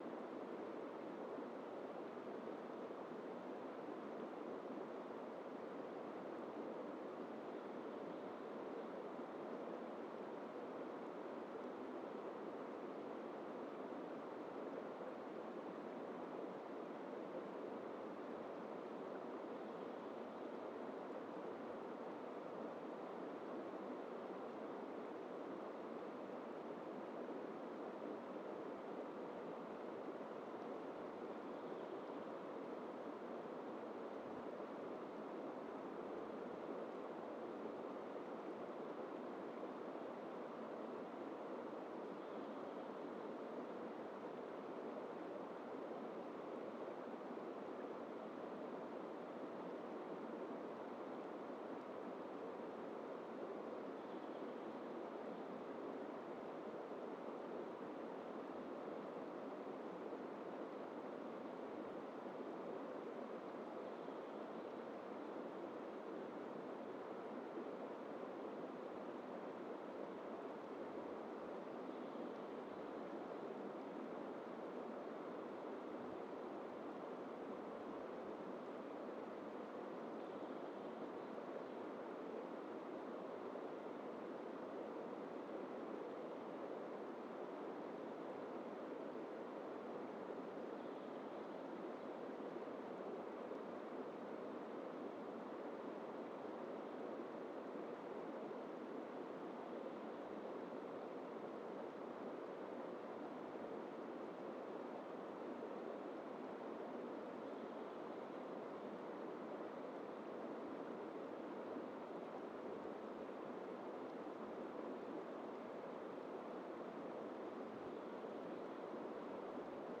Quellrauschen250.mp3